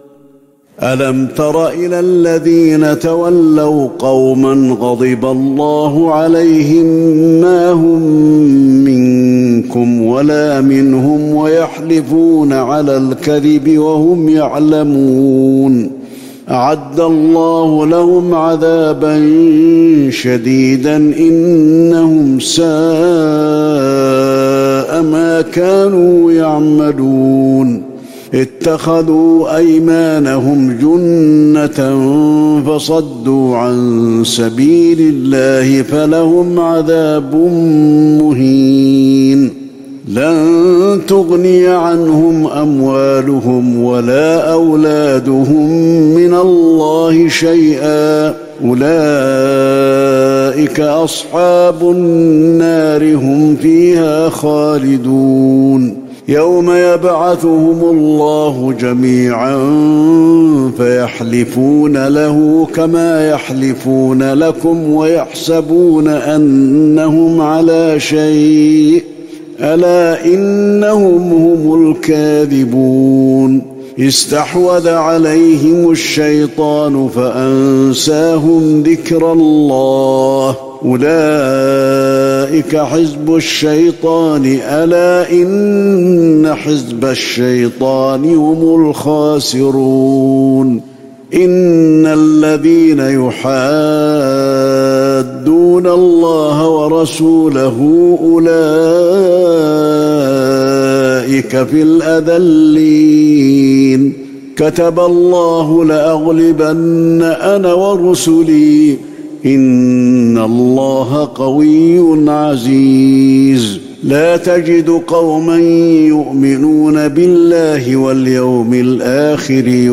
تهجد ٢٨ رمضان ١٤٤١هـ من سورة المجادلة ١٤-النهاية والحشر ١-١٧ > تراويح الحرم النبوي عام 1441 🕌 > التراويح - تلاوات الحرمين